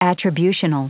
Prononciation du mot attributional en anglais (fichier audio)
Prononciation du mot : attributional